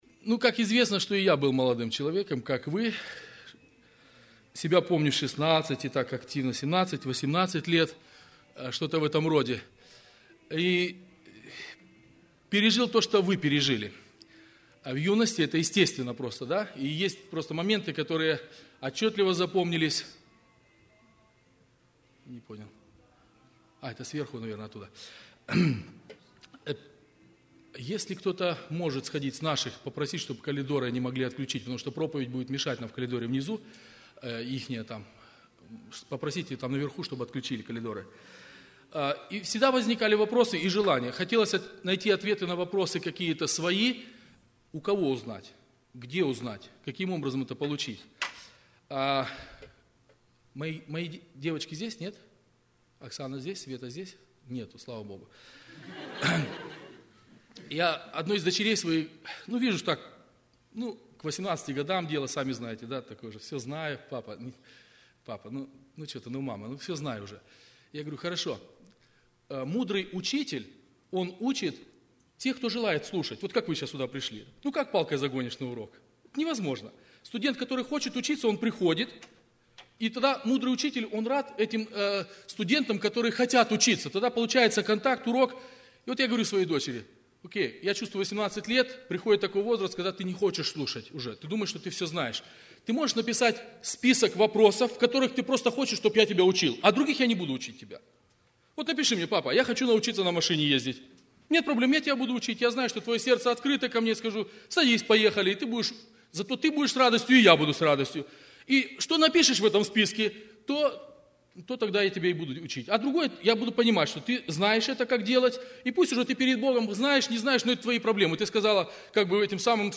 01 Проповедь.mp3